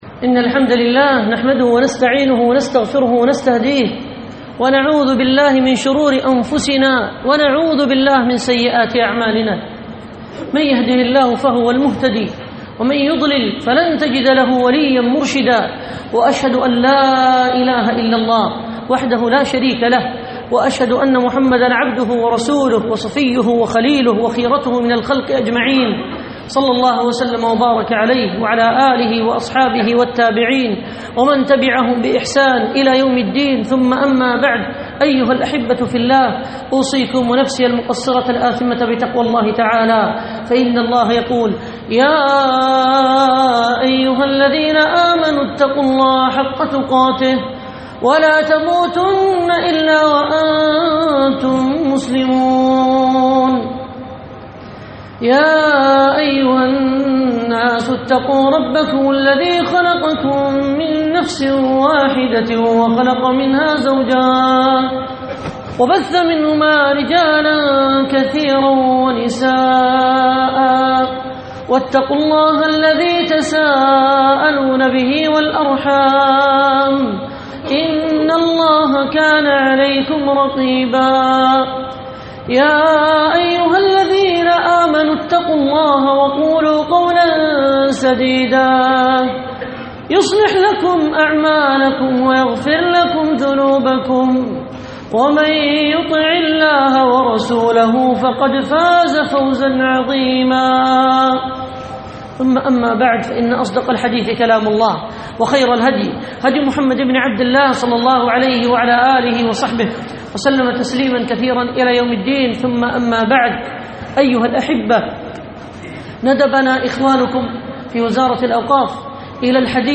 Demo - Audio from Friday sermon - Islamic Friday prayer